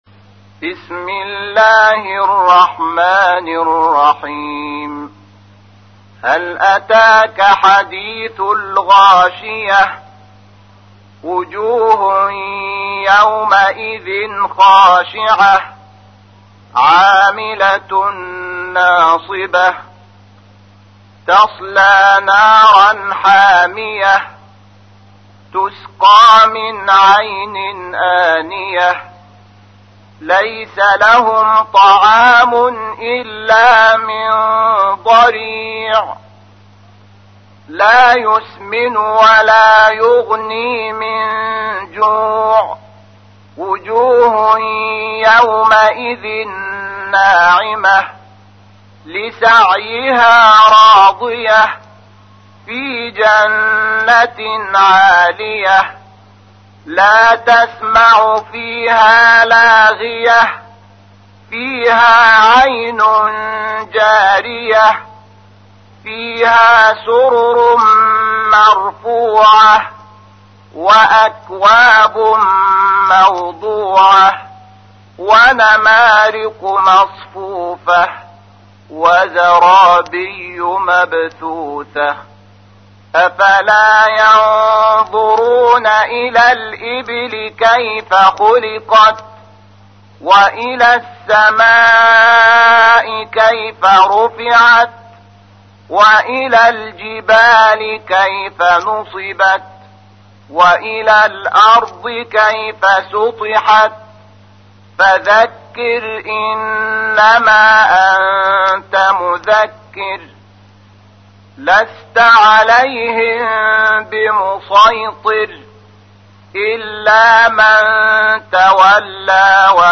تحميل : 88. سورة الغاشية / القارئ شحات محمد انور / القرآن الكريم / موقع يا حسين